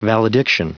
Prononciation du mot valediction en anglais (fichier audio)
valediction.wav